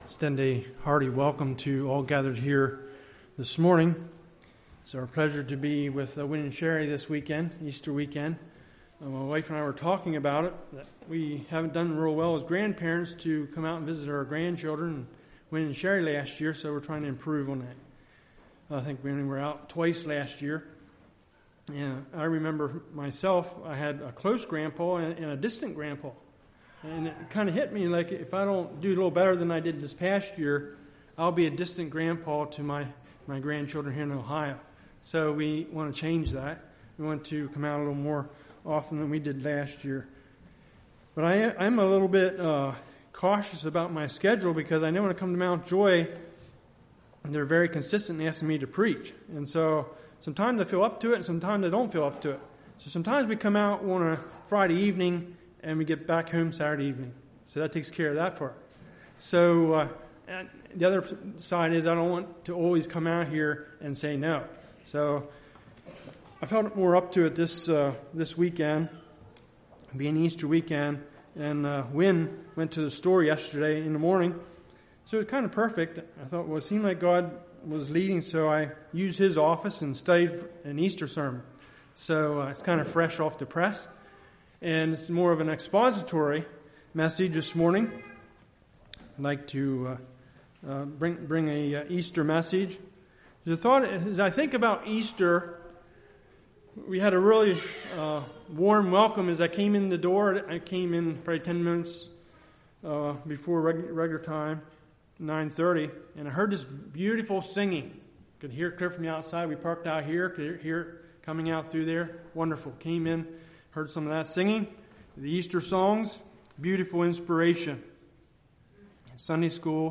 Sermons
Congregation: Mount Joy